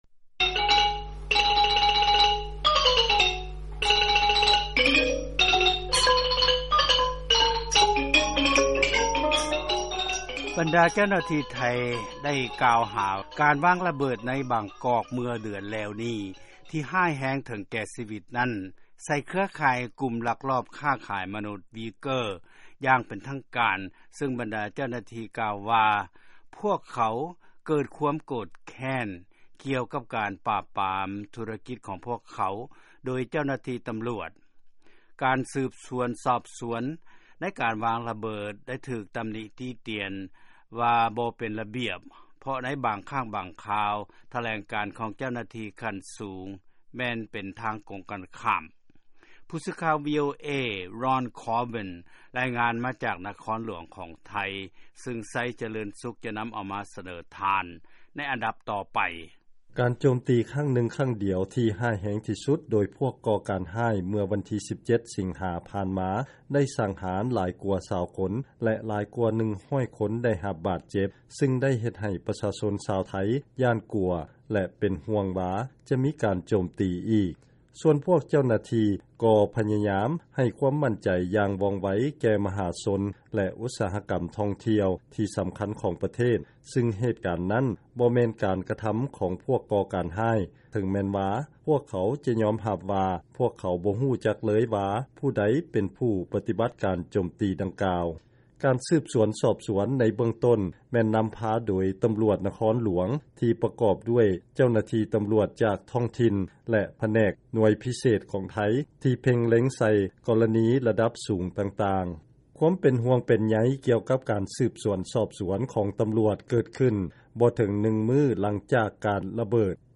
ຟັງລາຍງານ ໄທ ລວບລວມ ຫຼັກຖານ ສຳຫລັບ ຄະດີ ວາງລະເບີດ ໃນບາງກອກ.